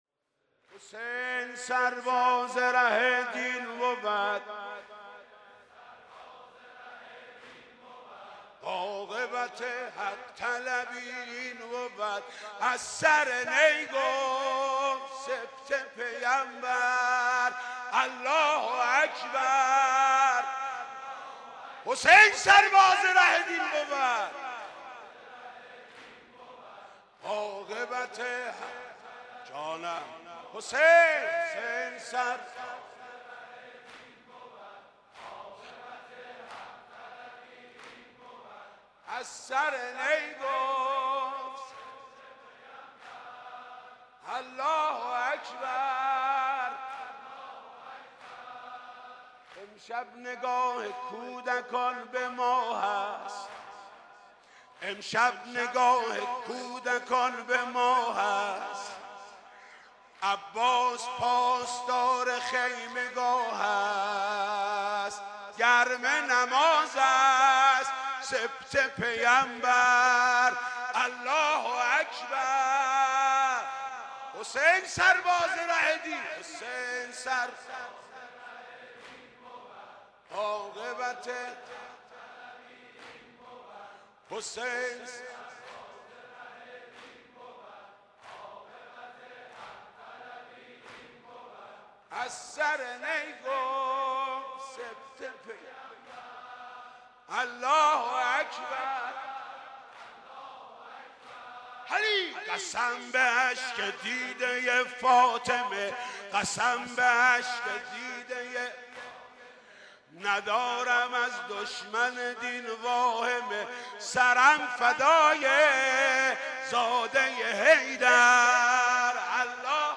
«محرم 1396» (شب تاسوعا) دودمه: حسین سرباز ره دین بود
«محرم 1396» (شب تاسوعا) دودمه: حسین سرباز ره دین بود خطیب: حاج محمود کریمی مدت زمان: 00:03:48